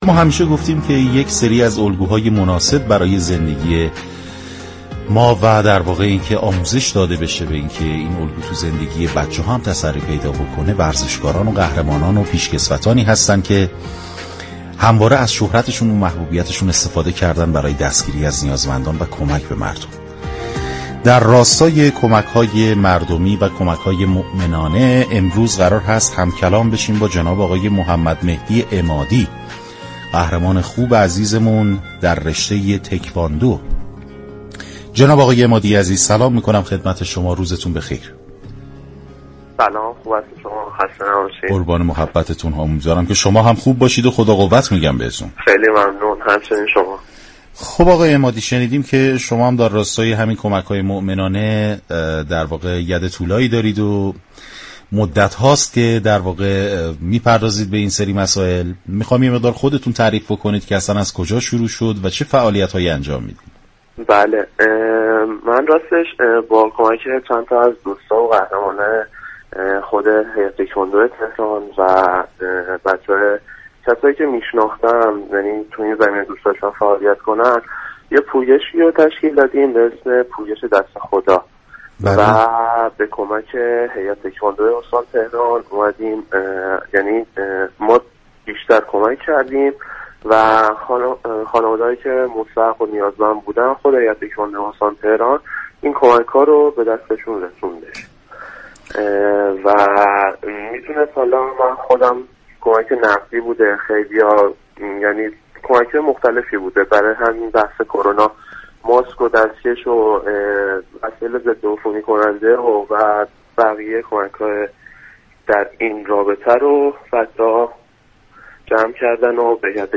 این ورزشكار جوان كشورمان درباره نحوه اجرای این كمك رسانی ها به رادیو ورزش گفت: شخصی در هیئت تكواندوی استان تهران مسئول شناسایی خانواده های مستحق است و گروه ما با جمع آوری این اقلام یا وجوه نقد از میان تكواندوكاران و در اختیار هیئت تكواندو قرار دادن نسبت به كمك به افراد محتاج اقدام می كنند.